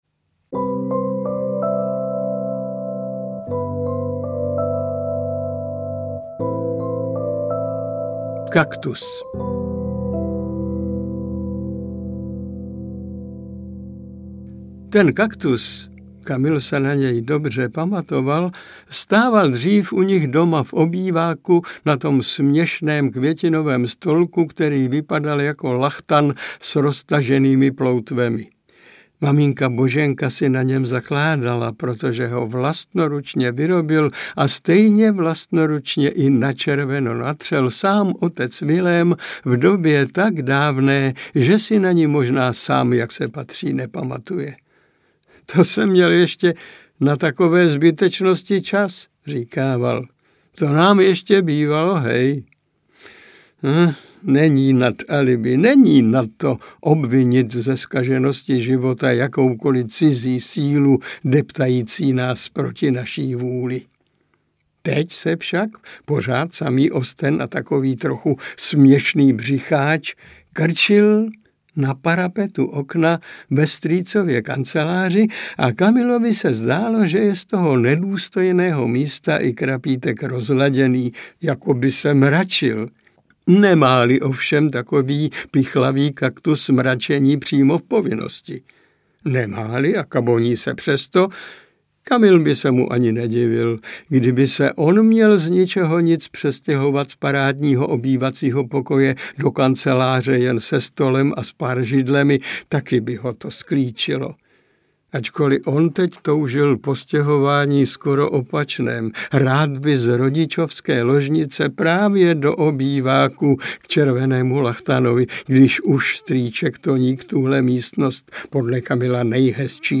Příliš mnoho pohřbů aneb Když květiny promluví audiokniha
Ukázka z knihy
• InterpretMiloň Čepelka